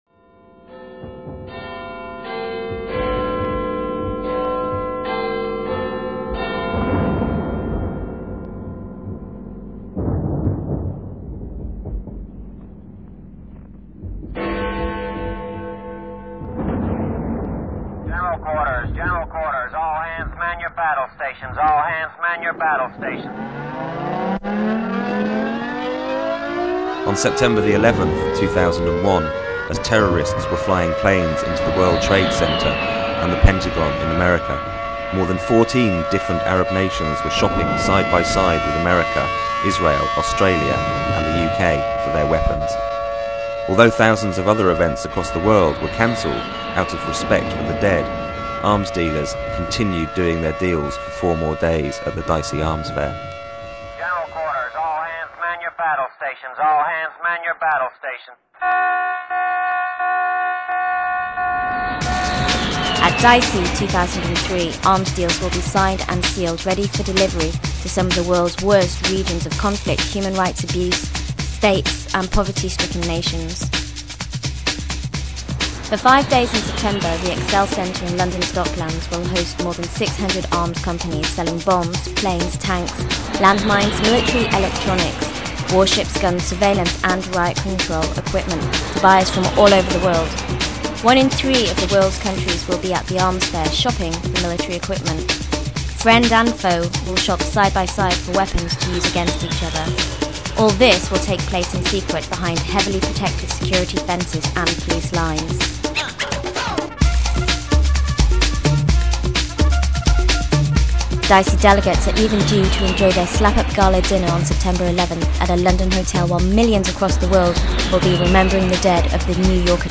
Download: bells - mp3 1.1M